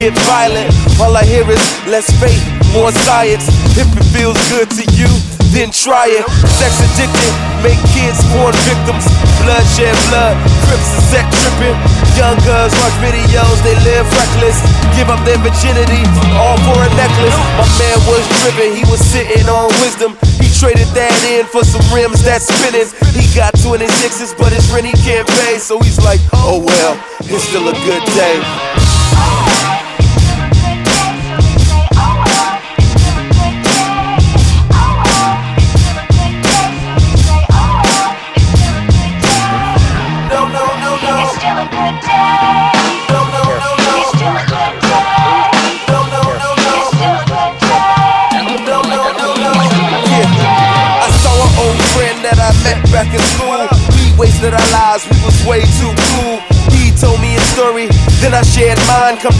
Rock-, Pop-, Motown-, Soul- und Black Gospel-Einflüsse
• Sachgebiet: Pop